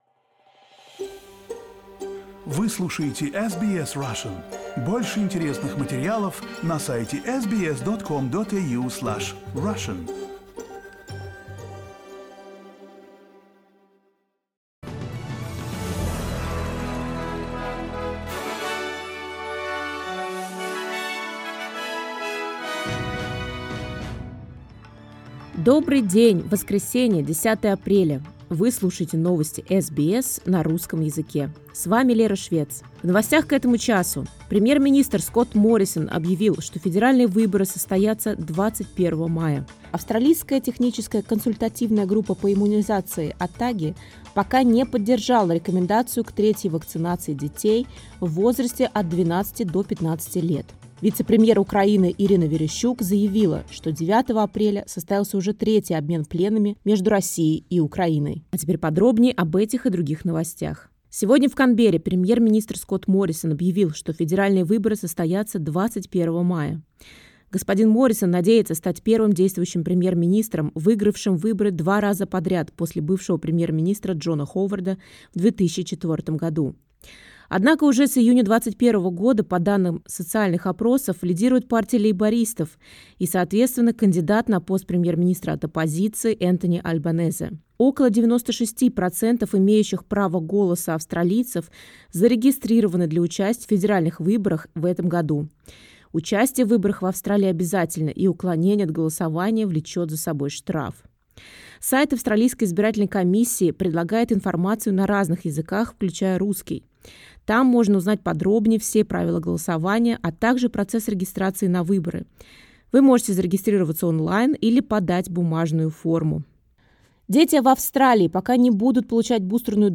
SBS news in Russian — 10.04